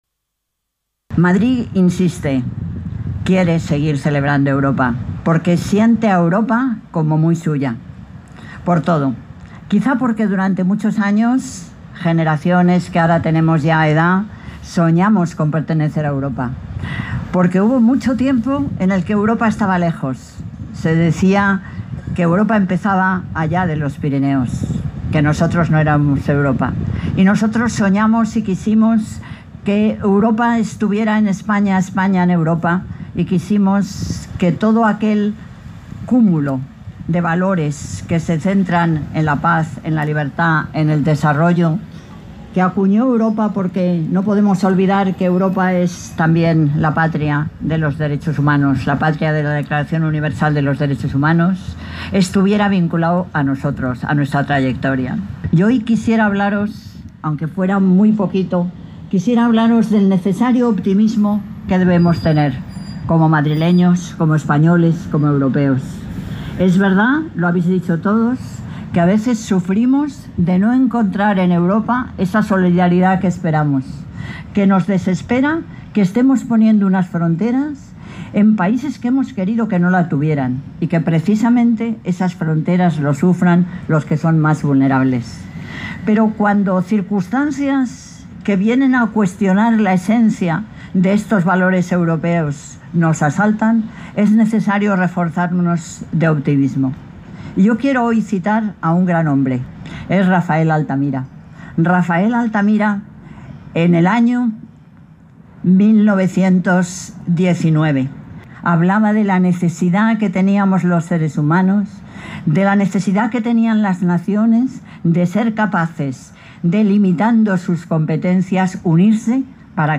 Ha sido izada en presencia de la alcaldesa de Madrid, Manuela Carmena, quien ha estado acompañada por una representación de legaciones diplomáticas, instituciones y organismos europeos.
MCarmenaDiaDeEuropa-09-05.mp3